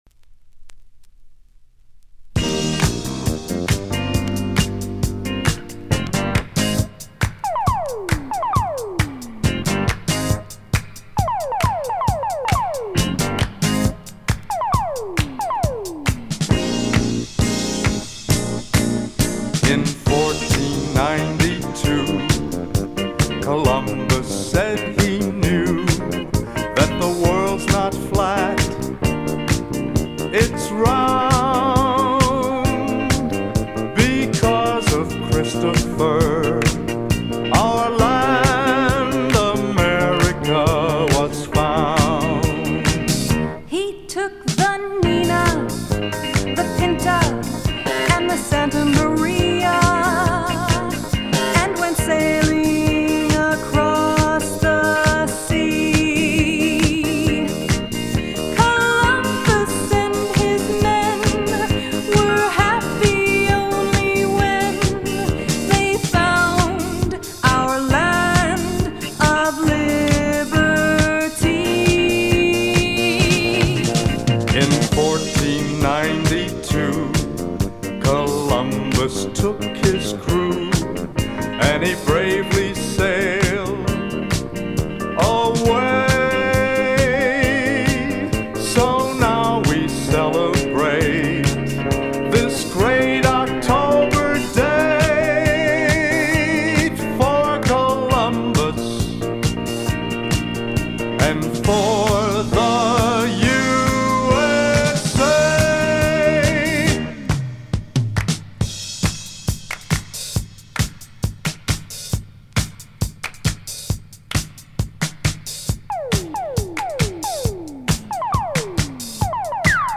Columbus Day themed disco track.